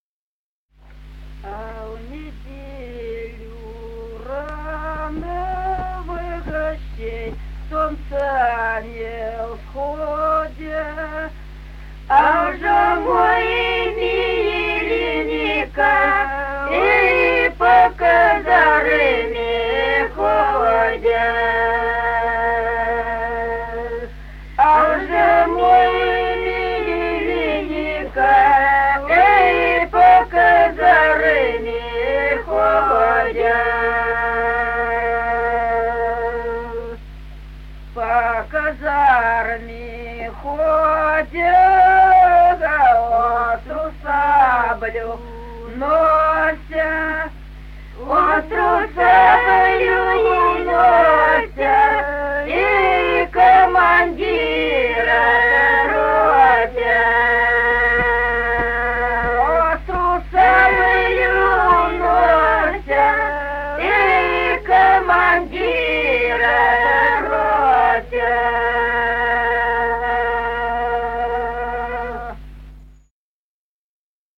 Песни села Остроглядово А в неделю рано.
Песни села Остроглядово в записях 1950-х годов